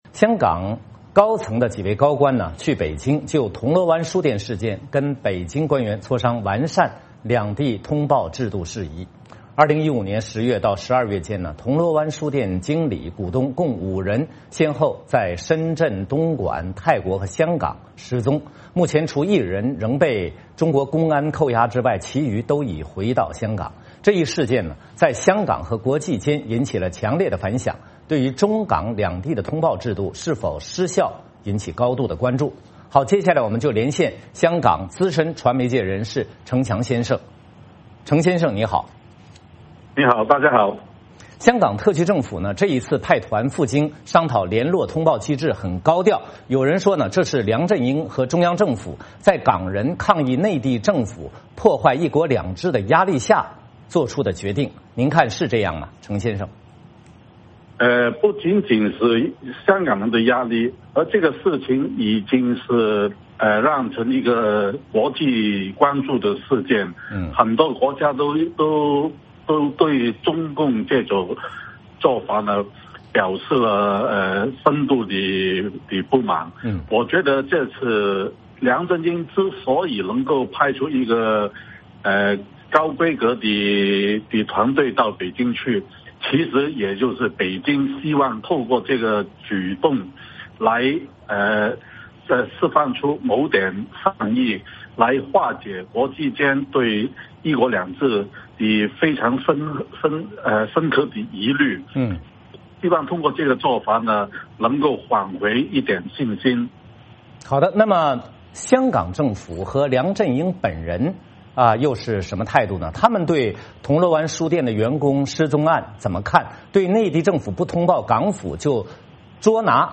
来我们连线香港资深传媒人士程翔先生......